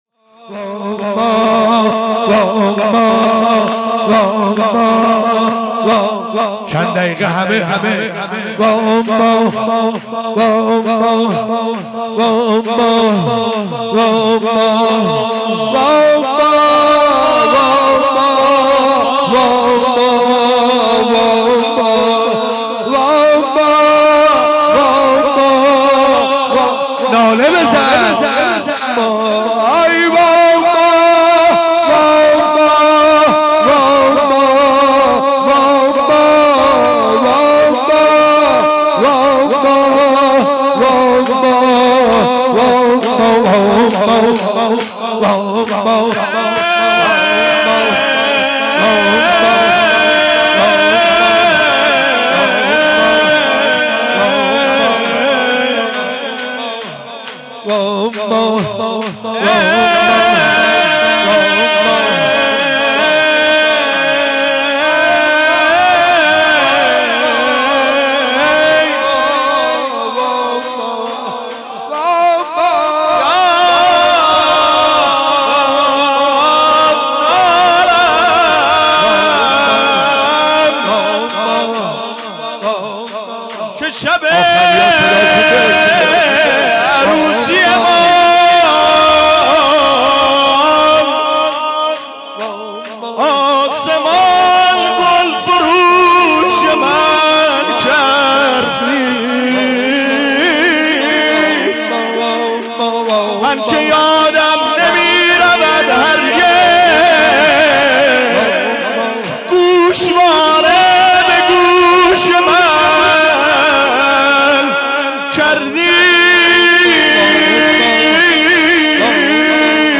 1 اسفند 96 - بیت الرضوان - شور - وا اماه